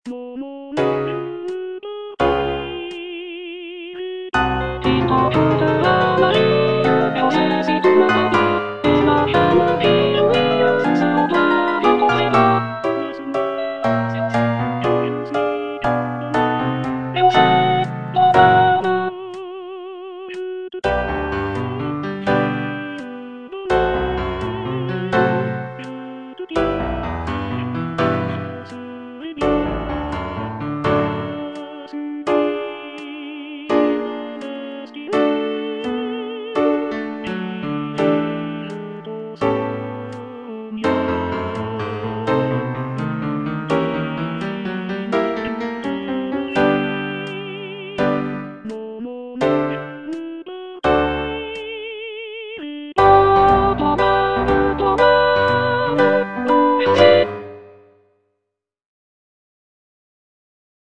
G. BIZET - CHOIRS FROM "CARMEN" Il t'en coutera la vie (soprano II) (Voice with metronome) Ads stop: auto-stop